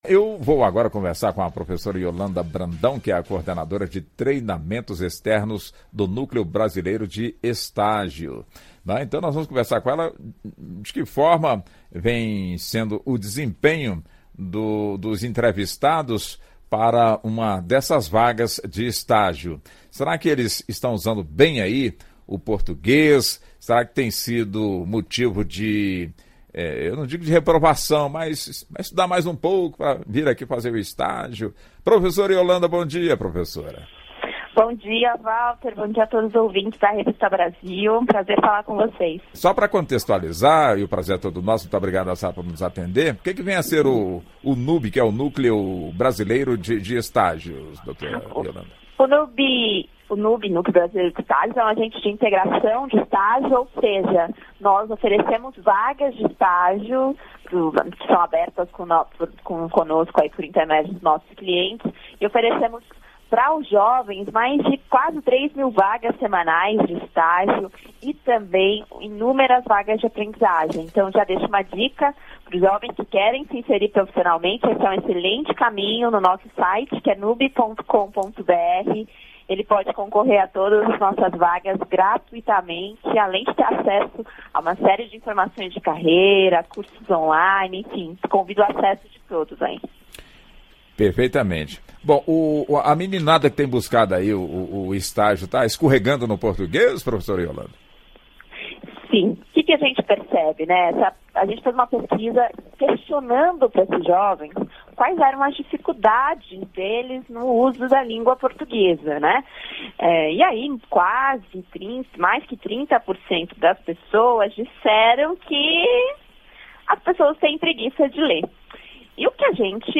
Entrevista: Saiba os motivos da alta reprovação de jovens em português